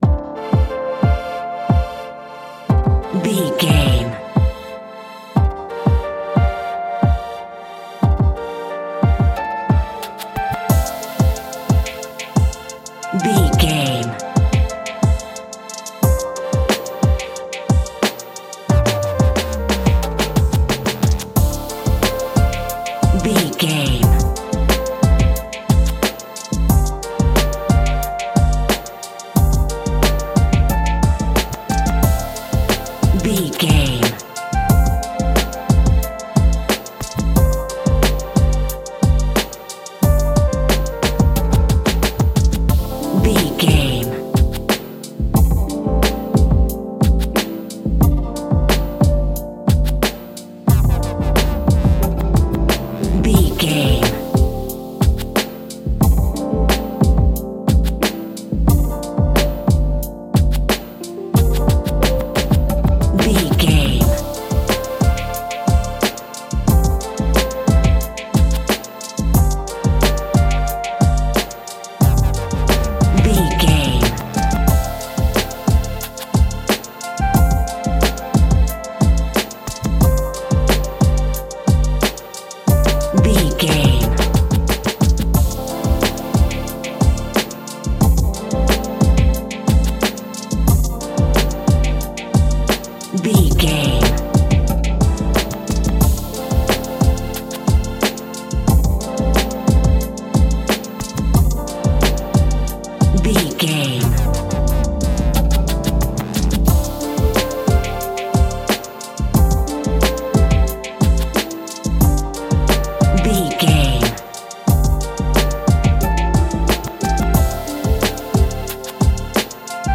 Aeolian/Minor
Slow
dreamy
meditative
ethereal
mellow
soothing